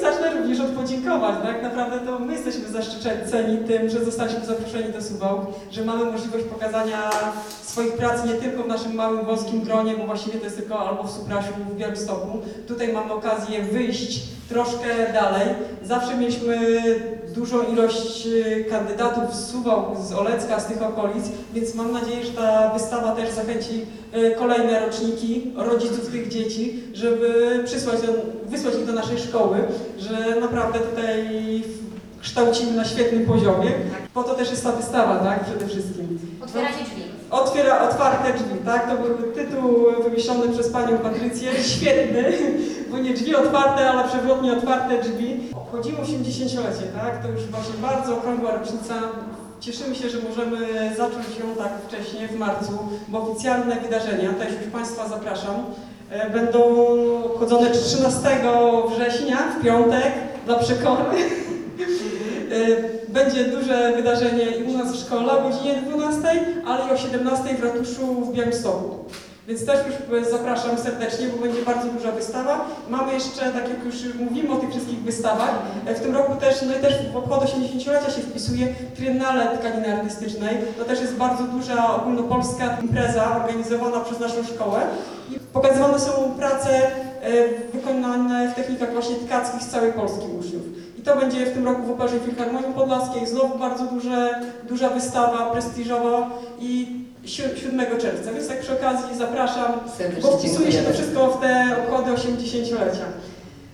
15 III 2024; Suwałki – Galeria Sztuki Współczesnej Chłodna 20 – wernisaż wystawy „Otwarte drzwi”